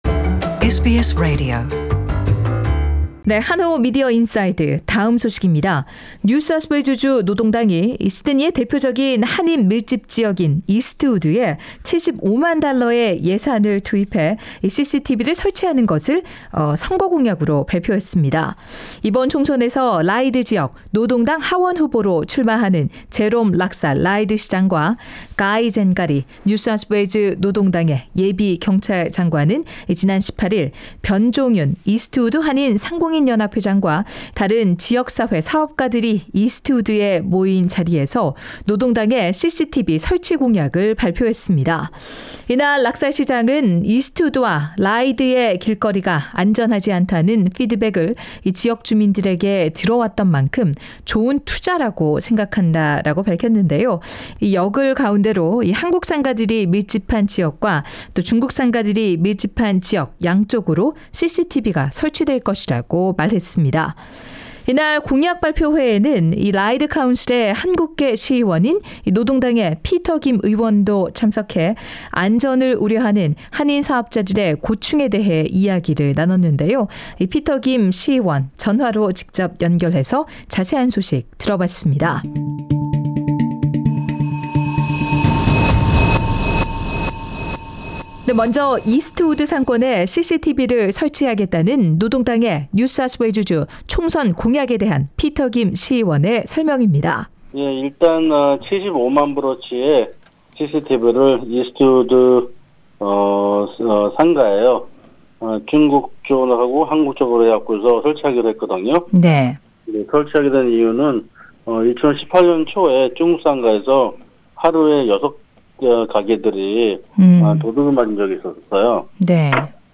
피터 김 의원 전화로 연결해서 자세한 소식 들어봤습니다.